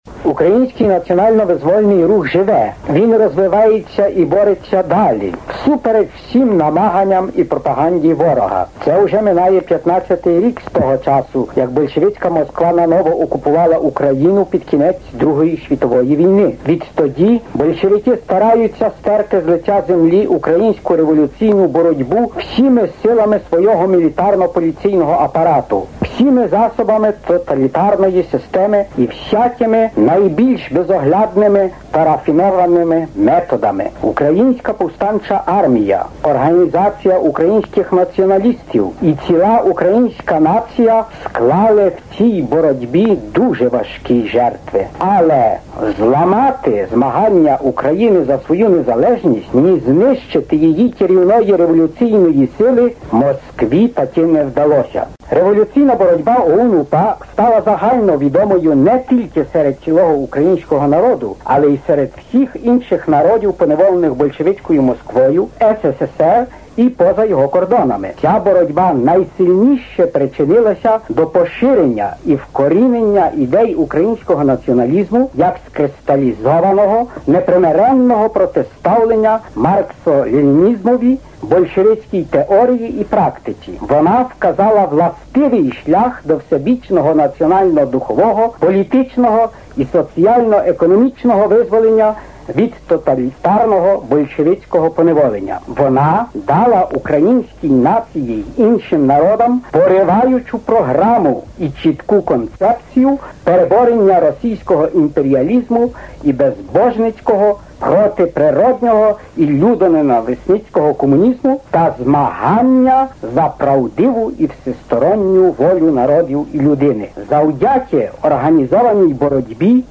Аудіозапис промови Степана Бандери, 1959 рік: